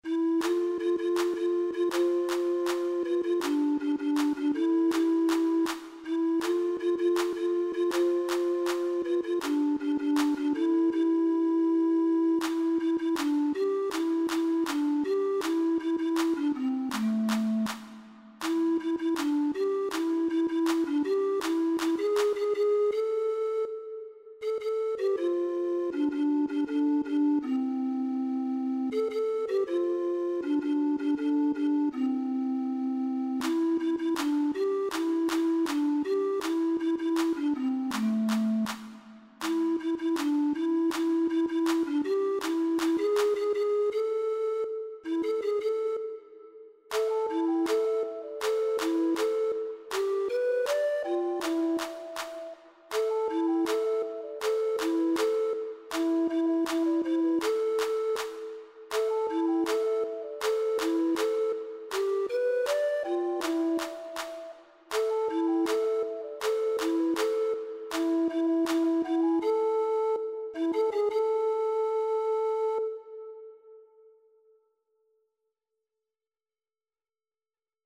2ª Voz